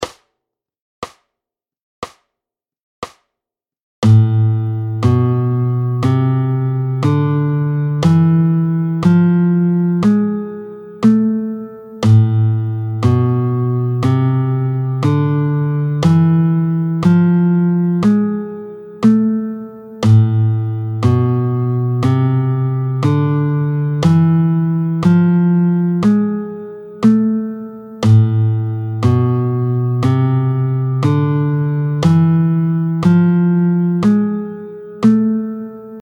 24-03 La mineur harmonique, tempo 60